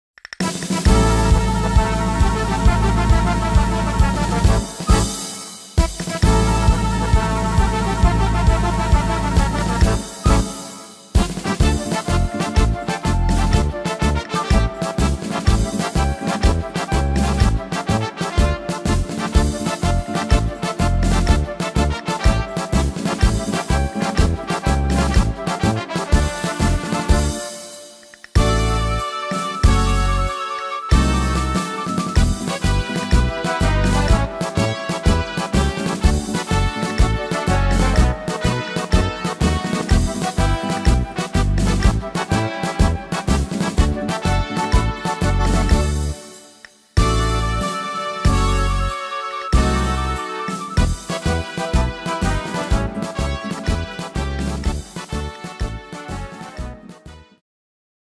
Tempo: 134 / Tonart: D-Dur